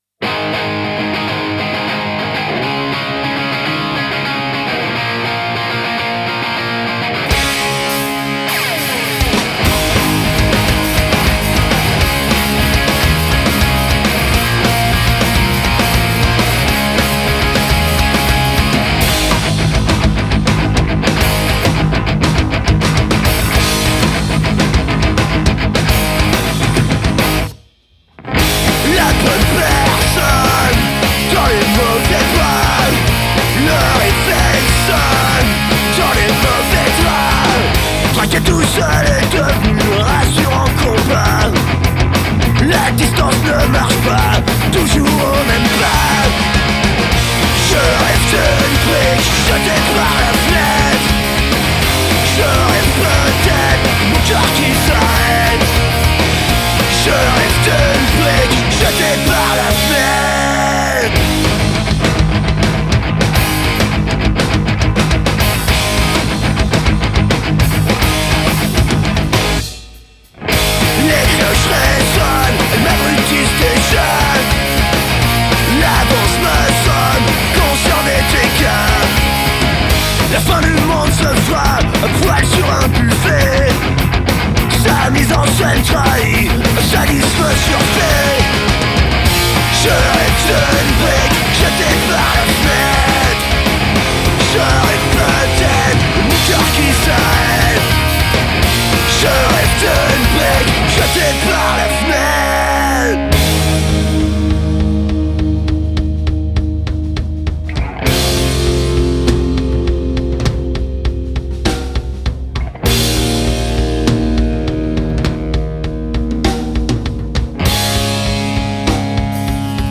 punk-rock alternatif français